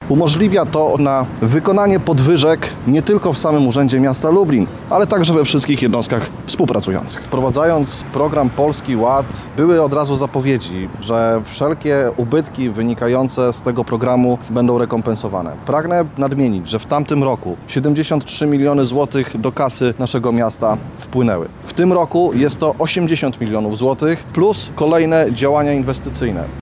– Rządowa subwencja, która została wkomponowana w miejski budżet podczas sesji rady miasta jest bardzo istotna – mówi radny PiS Piotr Popiel.